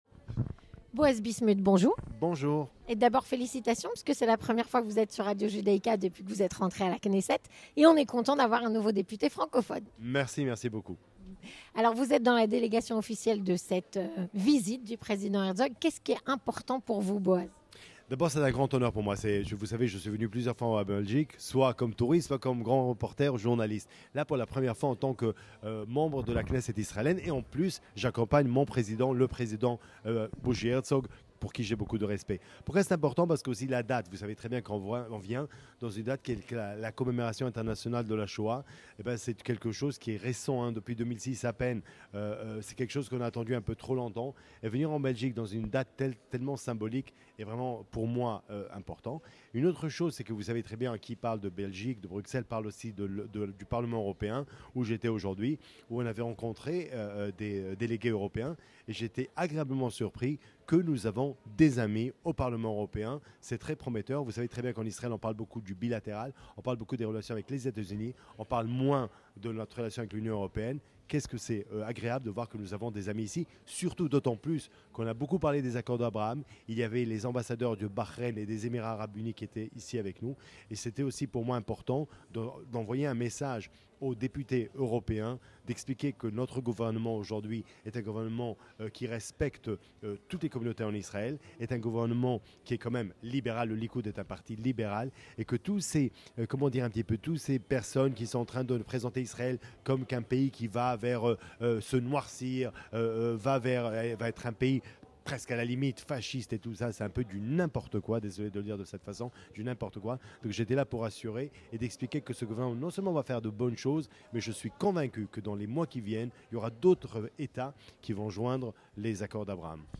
Boaz bismuth, Député israélien du Likoud (26/01/2023)